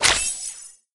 emit_tickets_01.ogg